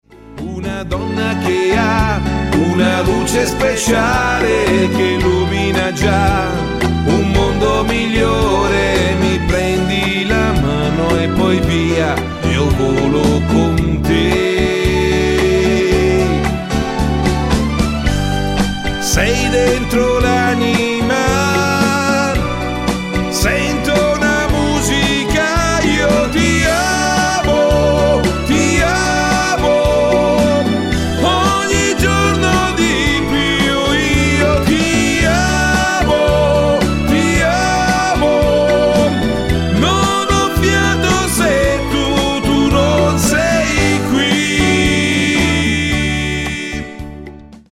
MODERATO  (2.56)